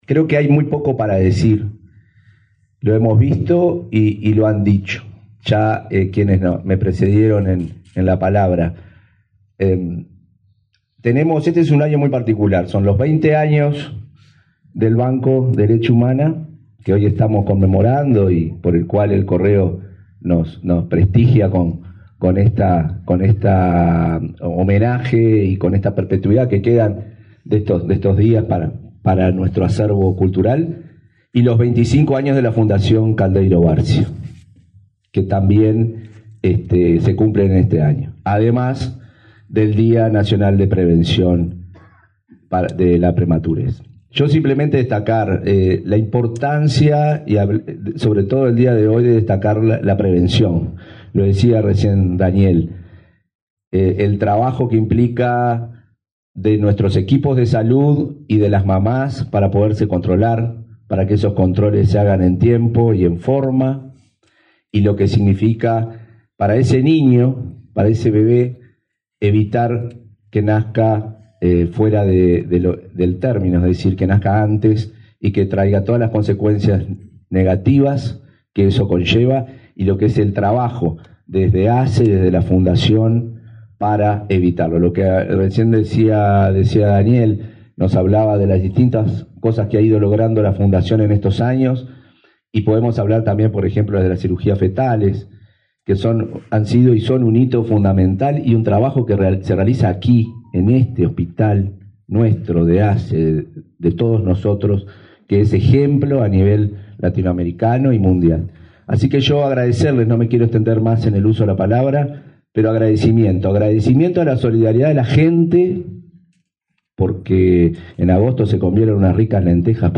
Palabras del presidente de ASSE, Marcelo Sosa
Palabras del presidente de ASSE, Marcelo Sosa 09/09/2024 Compartir Facebook X Copiar enlace WhatsApp LinkedIn El presidente de la Administración de los Servicios de Salud del Estado (ASSE), Marcelo Sosa, participó, este lunes 9 en el hospital Pereira Rossell, en el acto de entrega de un sello conmemorativo al Banco de Leche, a 20 años de la implementación del servicio.